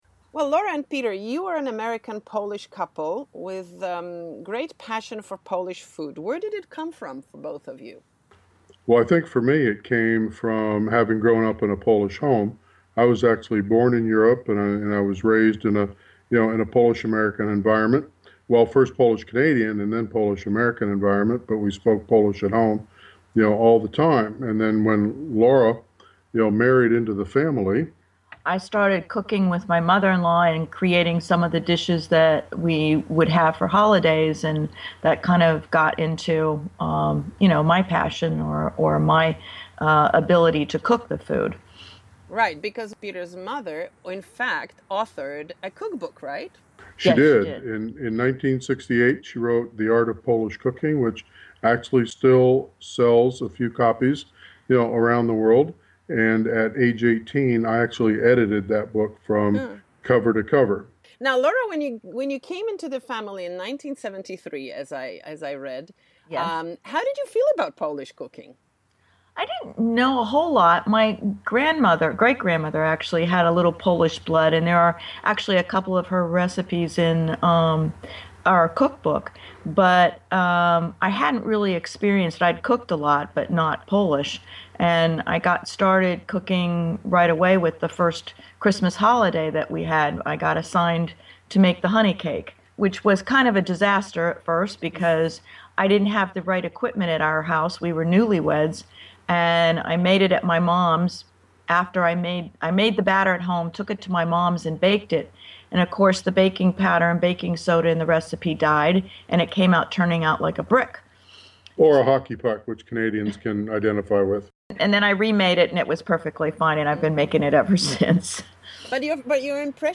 POLcast is an English language podcast, a colourful audio magazine delivered directly to your smartphone, computer or tablet. POLcast is created for everybody interested in Poland.